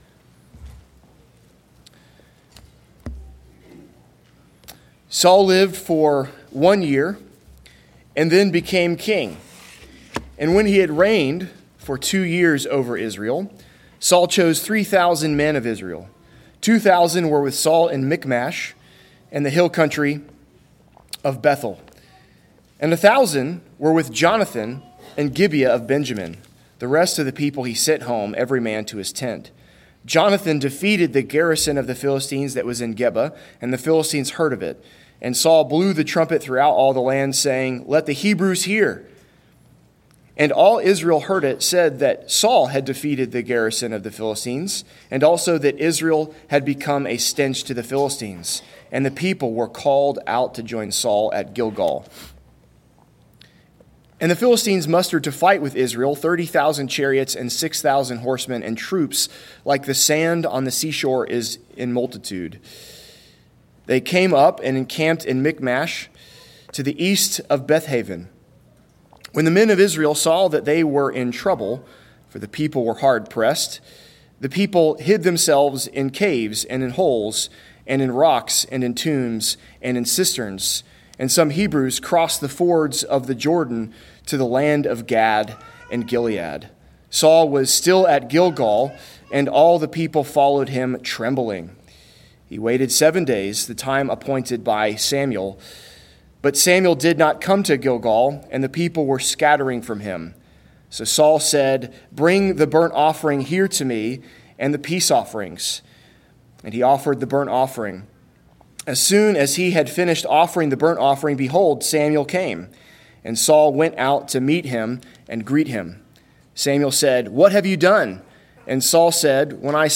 Galatians 2.17-19 Service Type: Sunday Worship Big Idea